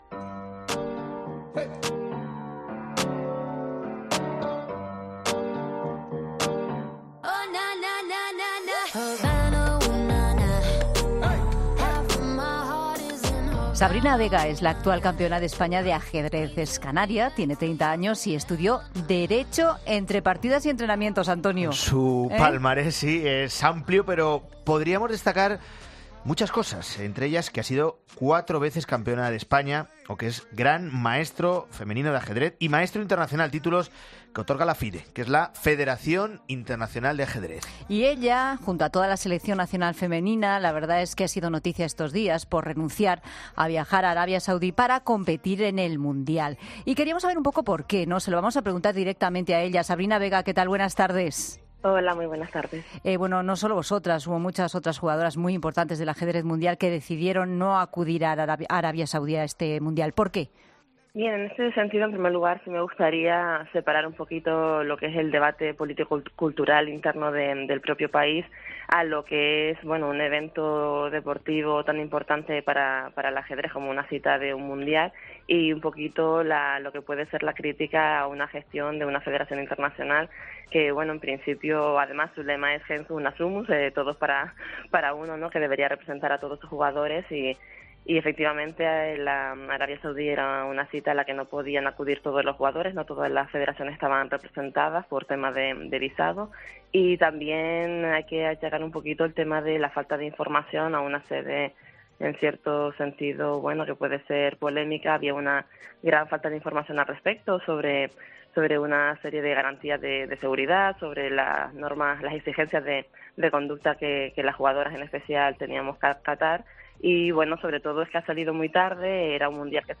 Entrevistas en Mediodía COPE